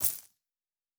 Coin and Purse 09.wav